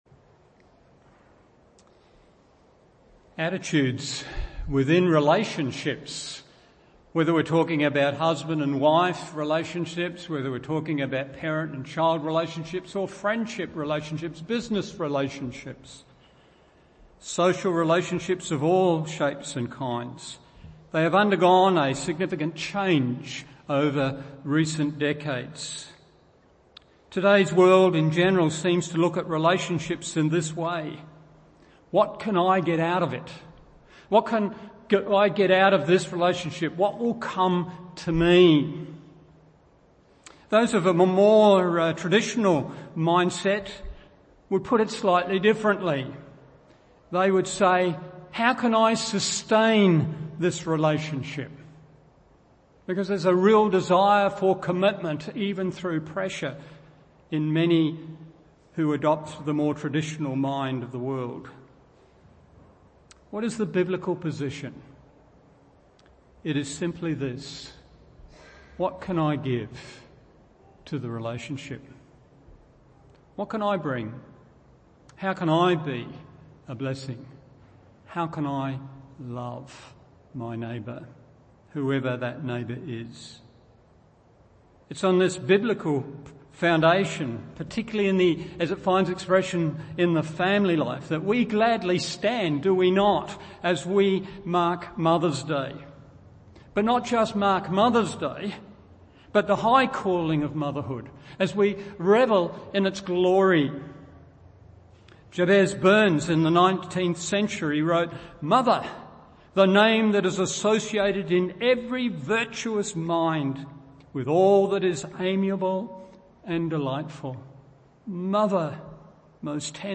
Morning Service Romans 16:13 1. The practice of motherhood honoured 2. The promise of motherhood celebrated 3. The provision of motherhood extended…